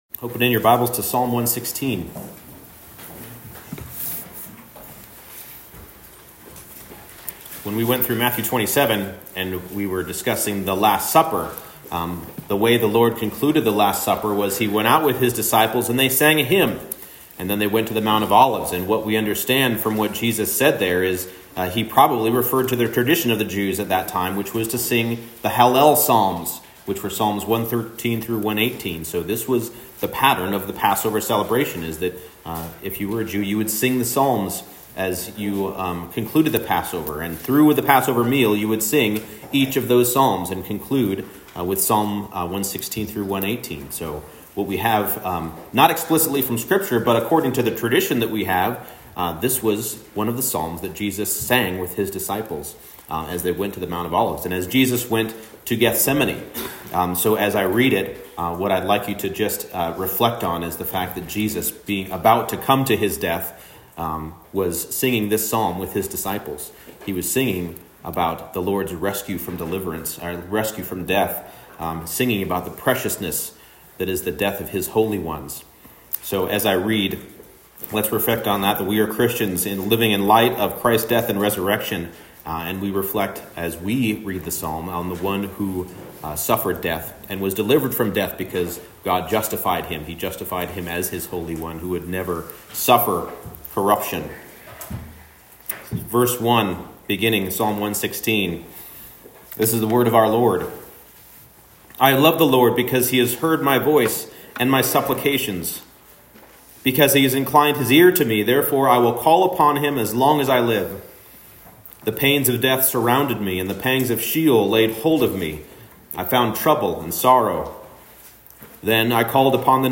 Psalm 116 Service Type: Morning Service The Lord is faithful to preserve His saints through the gravest trouble.